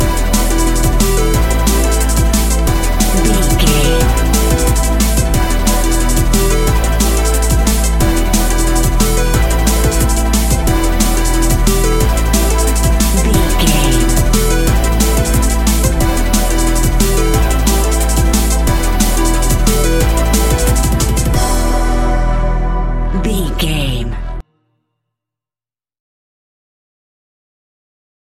Fast paced
Aeolian/Minor
Fast
aggressive
dark
driving
energetic
intense
futuristic
synthesiser
drum machine
electronic
sub bass
synth leads
synth bass